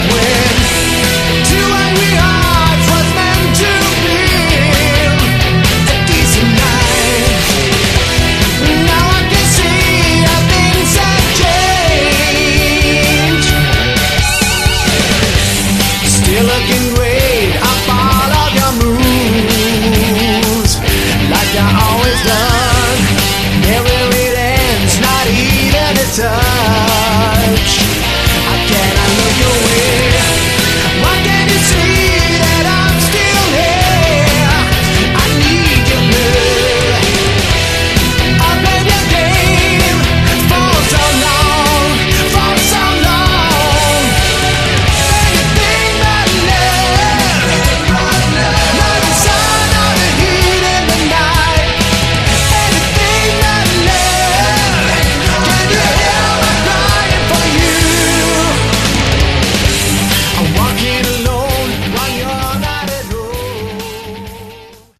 Category: Melodic Hard Rock
Vocals
Guitar
Bass
Keyboards
Drums